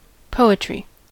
poetry-us.mp3